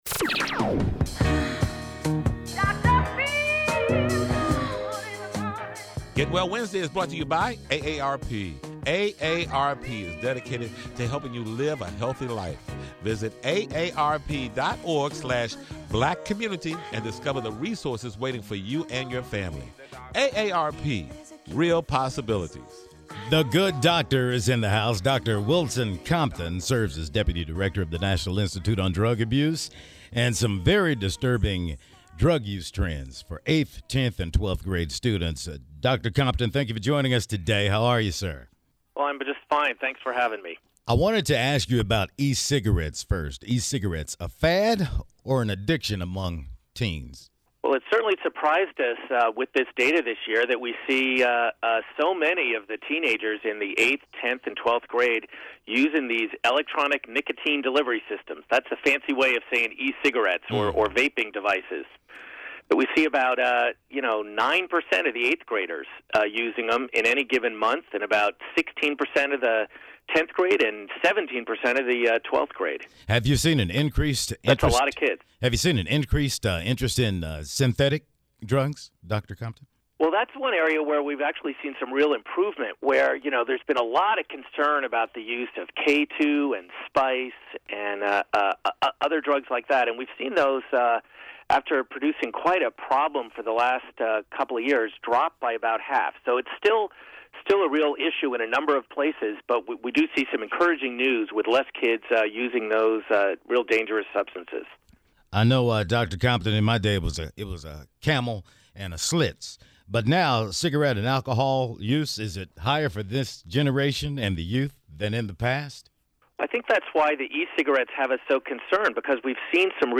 Get Well Wednesdays: Dr. Wilson Compton Talks Teens and Drugs